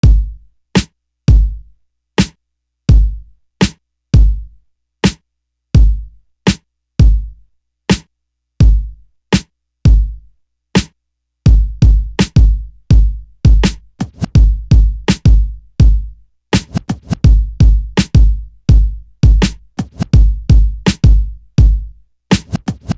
For The Record Drum.wav